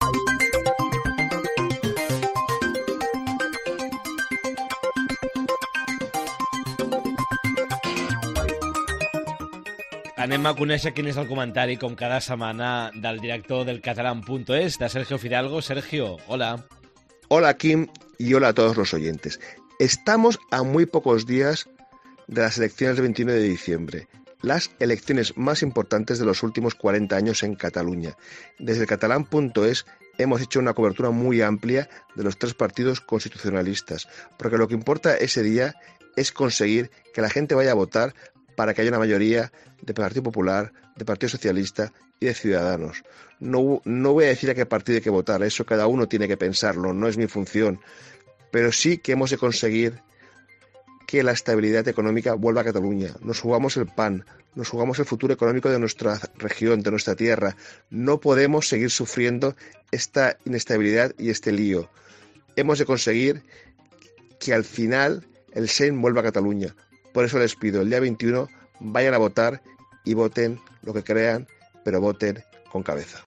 Comentari